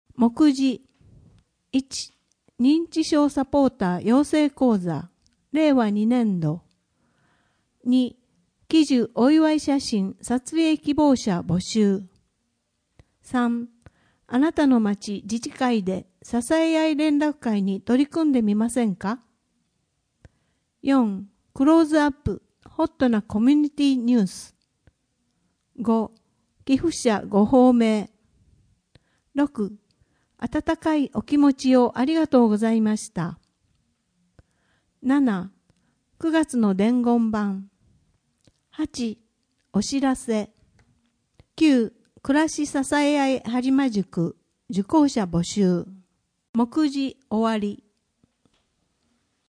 声の広報